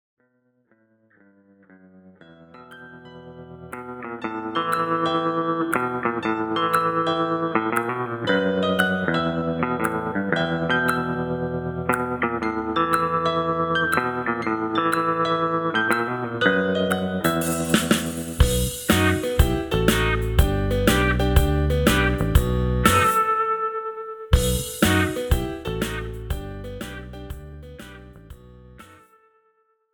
This is an instrumental backing track cover.
Key – E
Without Backing Vocals
No Fade